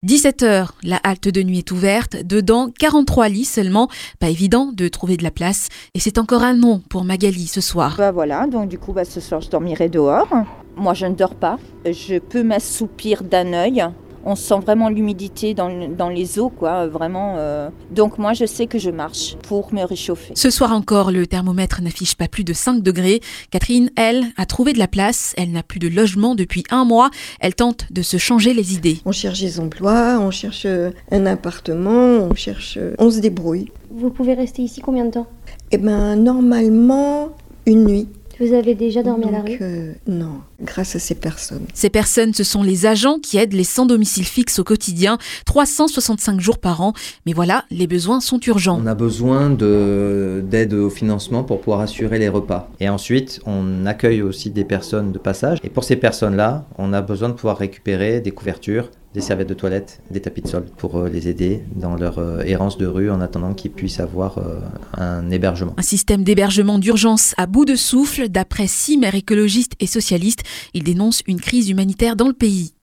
est allée à leur rencontre dans les rues de Nice.